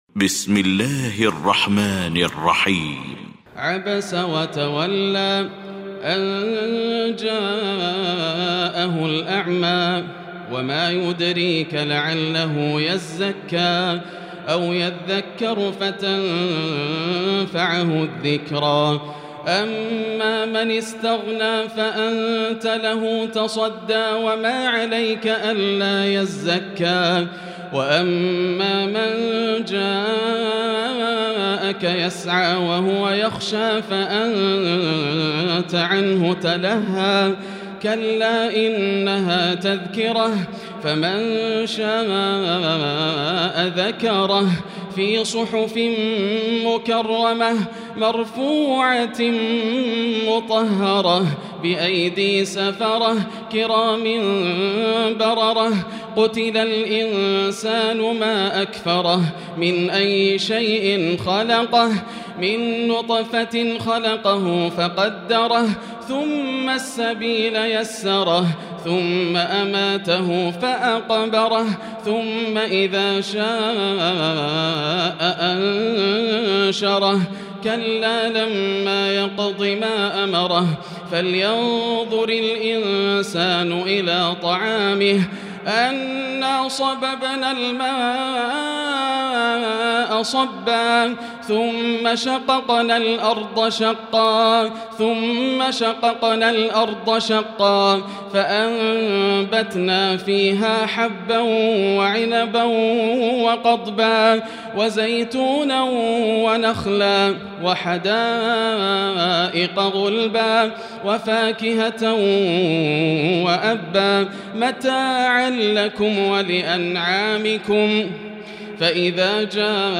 المكان: المسجد الحرام الشيخ: فضيلة الشيخ ياسر الدوسري فضيلة الشيخ ياسر الدوسري عبس